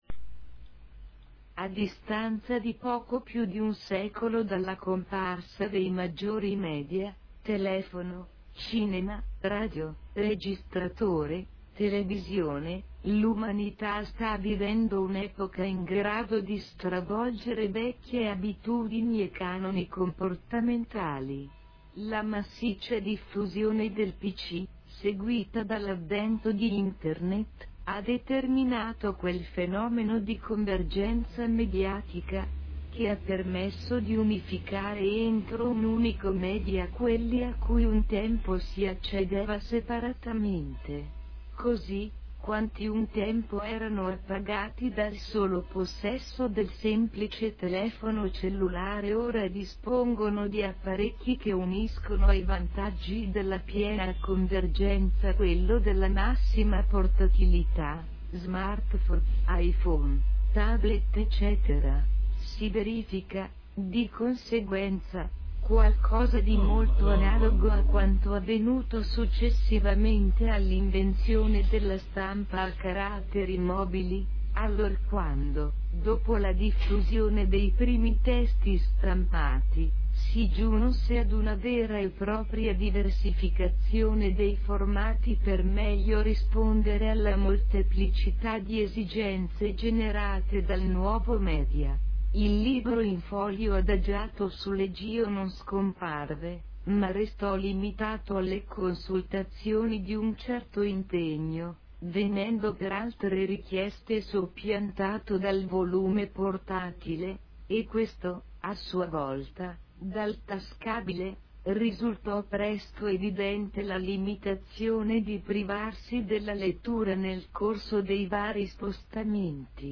SINTESI VOCALE